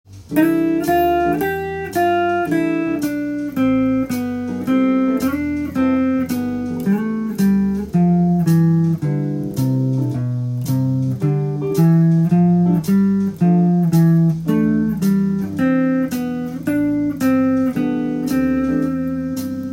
４分音符で適当に弾いてみる
自分の見えるポジションのドレミを弾いていきます。